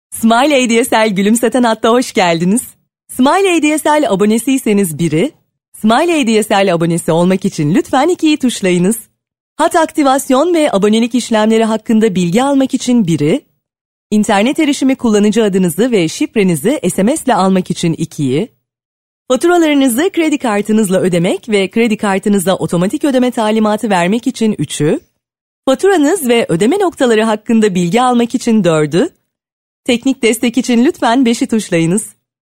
Turkish female voice over talent, turkish e-learning female voice, turkish female narrator
Sprechprobe: Industrie (Muttersprache):